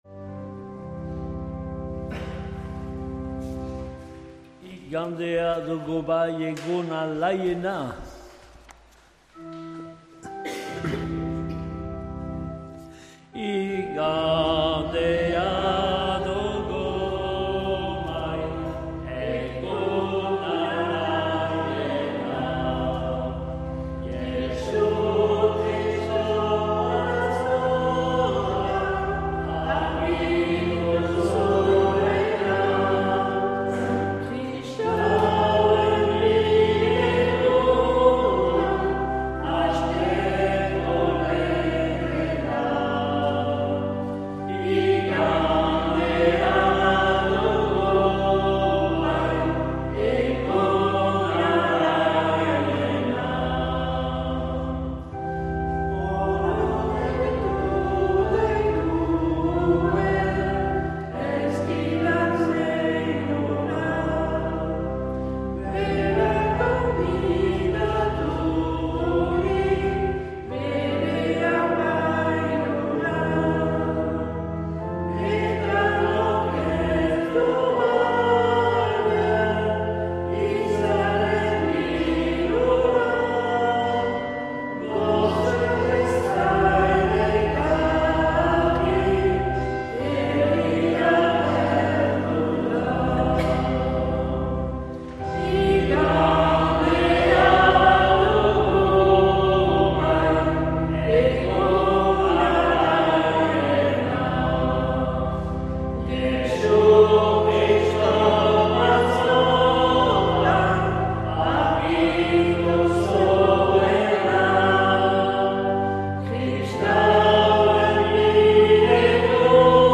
Accueil \ Emissions \ Vie de l’Eglise \ Célébrer \ Igandetako Mezak Euskal irratietan \ 2025-11-16 Urteko 33.